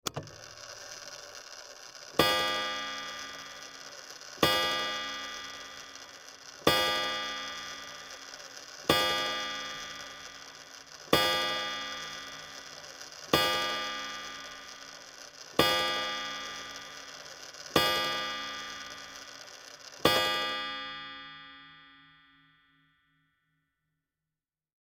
دانلود صدای ساعت 24 از ساعد نیوز با لینک مستقیم و کیفیت بالا
جلوه های صوتی